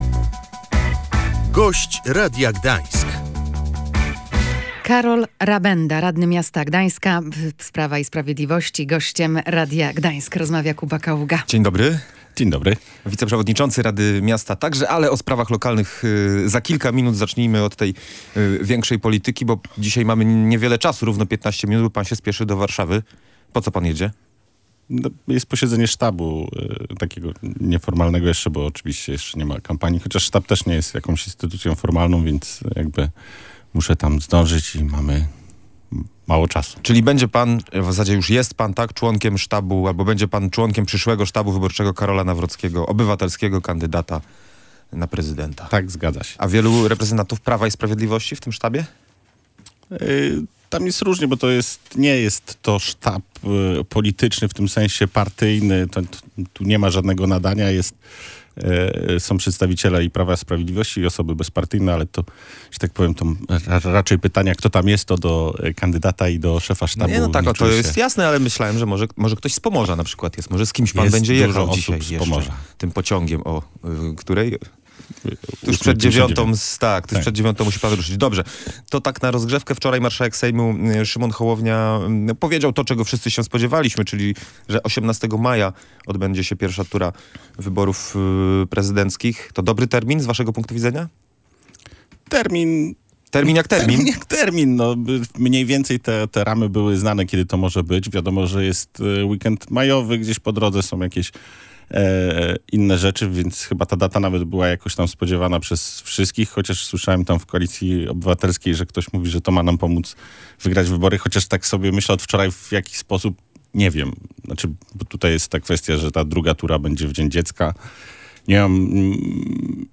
Decyzja dotycząca zamknięcia Mostu Siennickiego została podjęta za późno – mówi poranny Gość Radia Gdańsk, Karol Rabenda. Gdański radny Prawa i Sprawiedliwości wskazuje, że miasto wiedziało o fatalnym stanie przeprawy od lat.